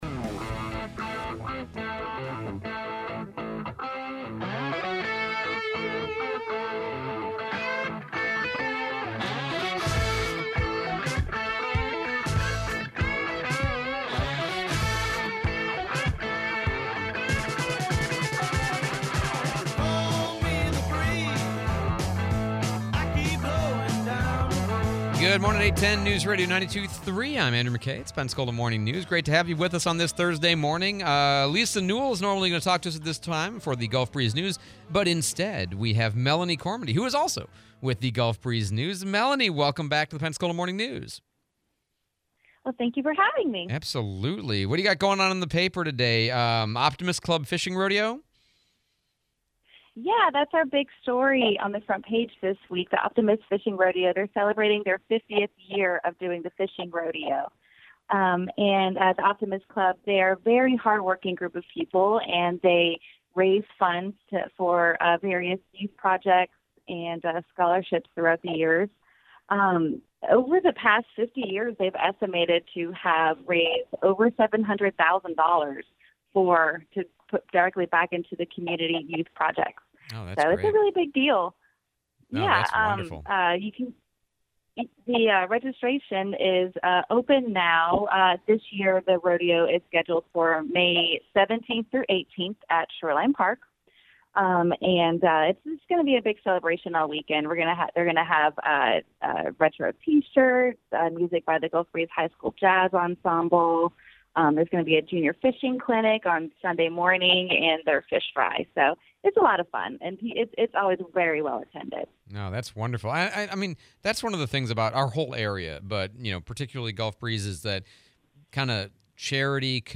04/10/25 8AM Gulf Breeze News Interview